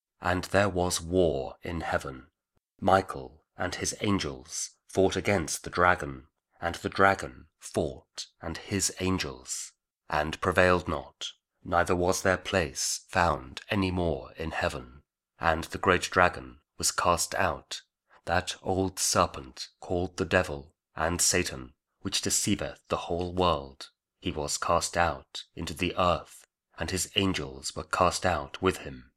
Revelation 12: 7-9 | Audio King James Version Bible | KJV